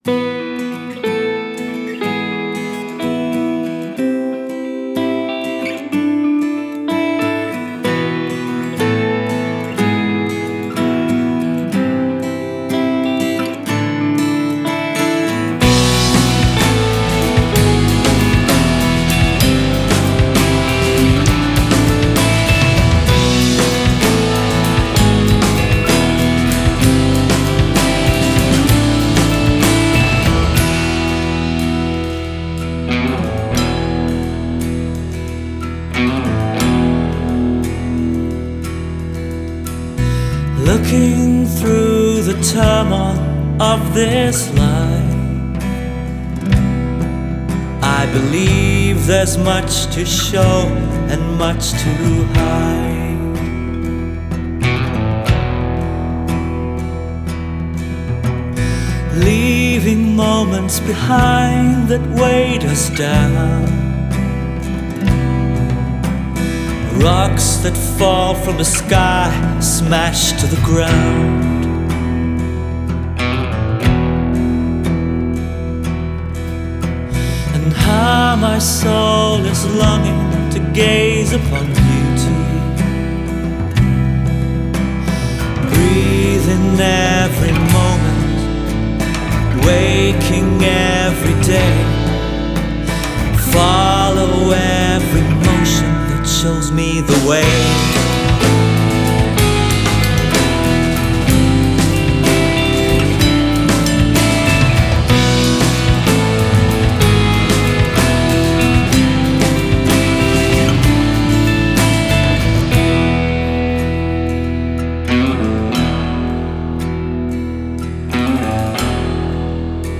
Scandinavian heavy/progressive rock